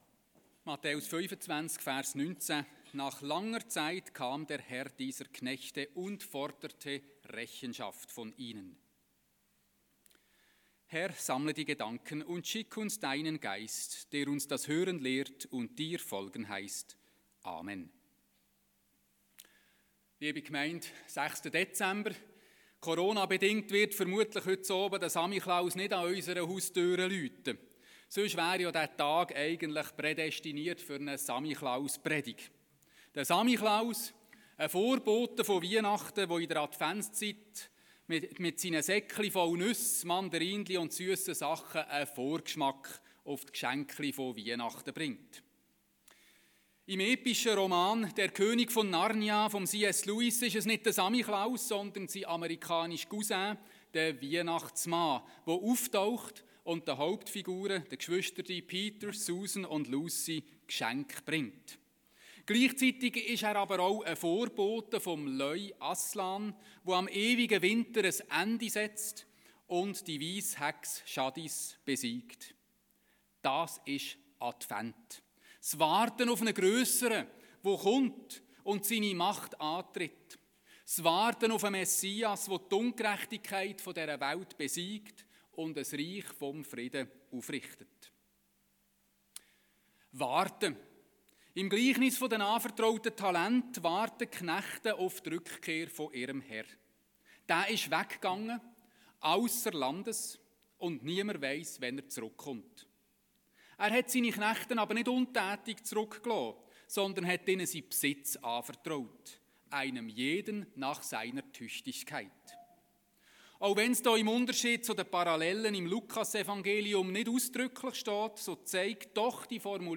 das erfahren Sie im Audiointerview(Audio Podcast